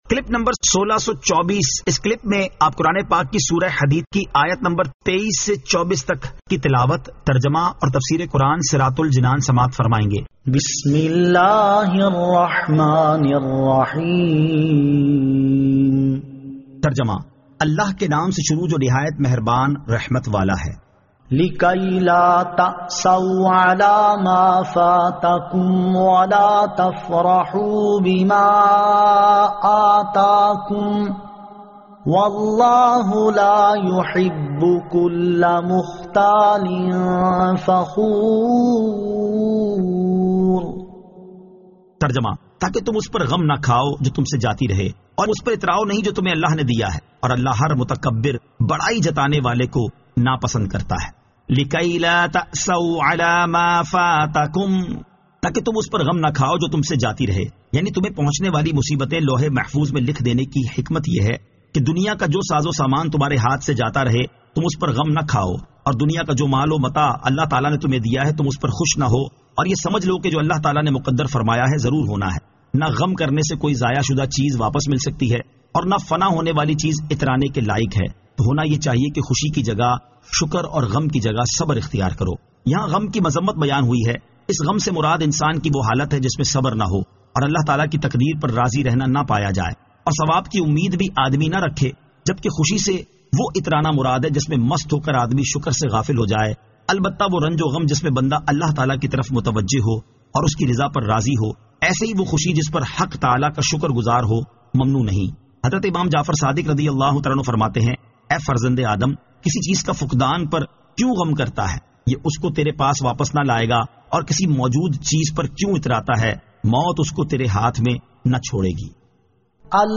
Surah Al-Hadid 23 To 24 Tilawat , Tarjama , Tafseer